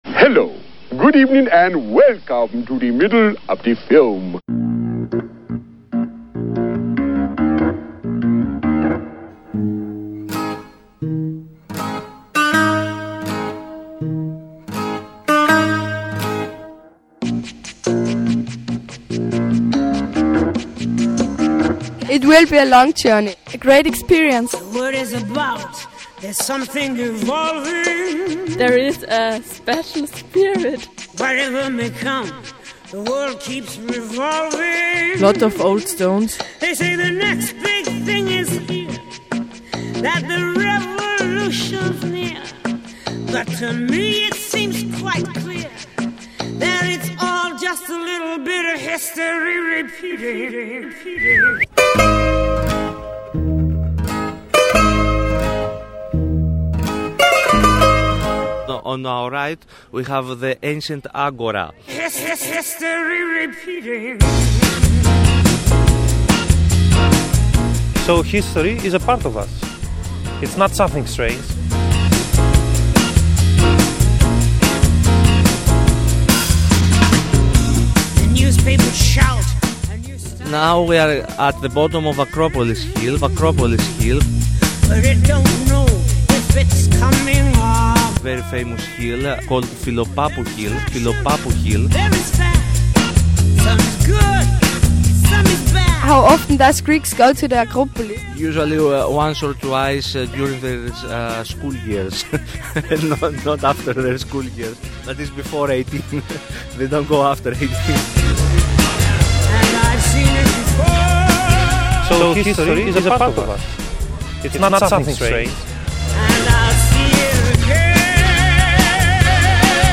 Radiofabrik, Salzburg Interview